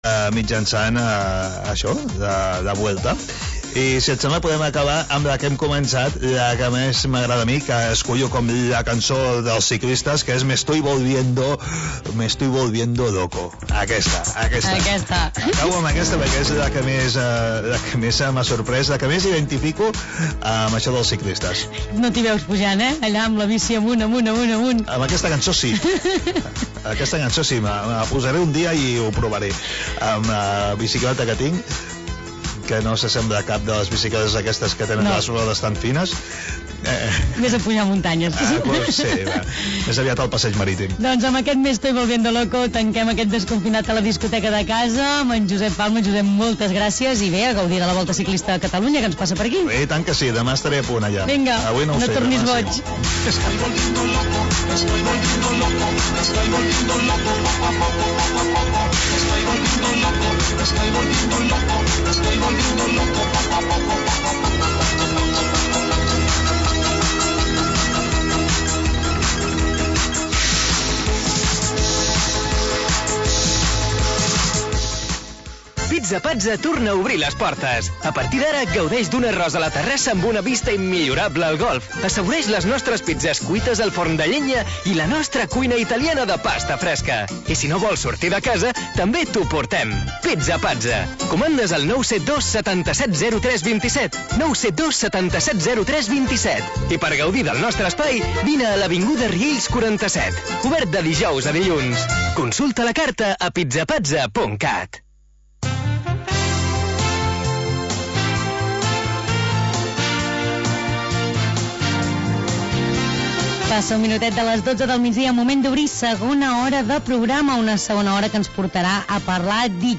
Magazín local d'entreteniment